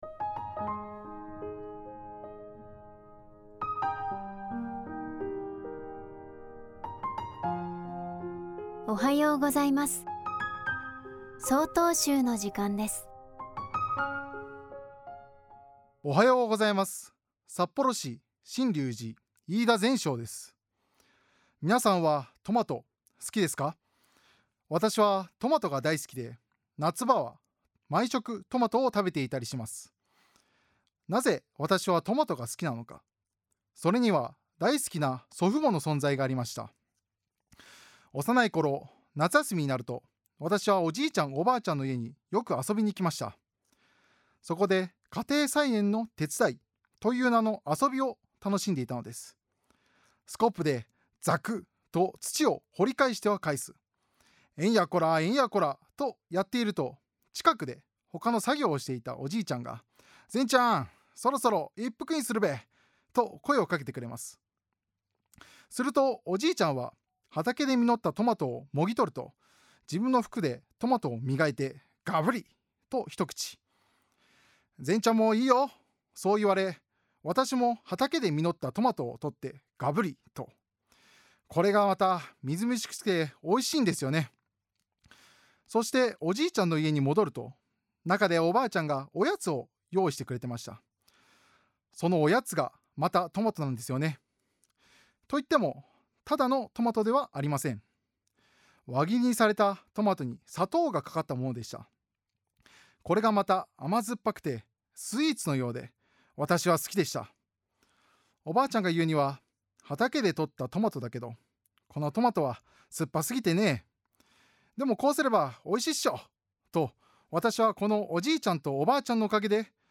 法話